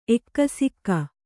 ♪ ekkasikka